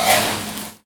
R - Foley 73.wav